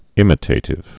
(ĭmĭ-tātĭv)